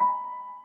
piano51.ogg